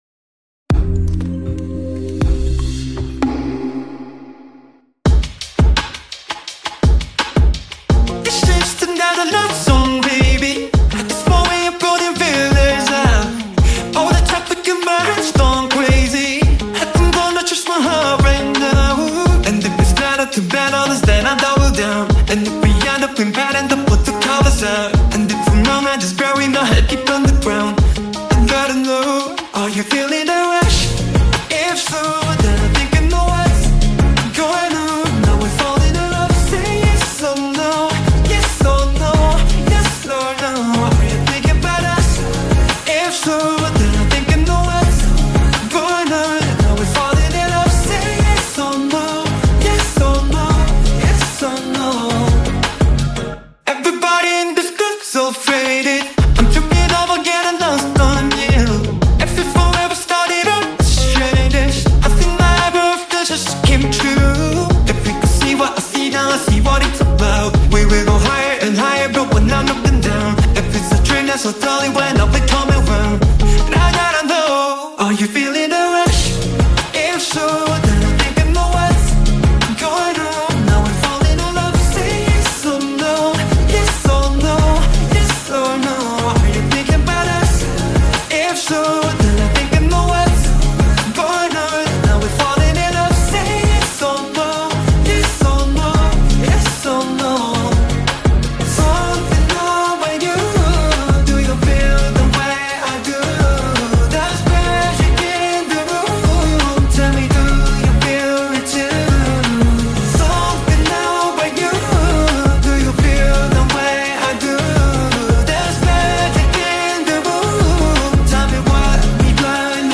کیپاپ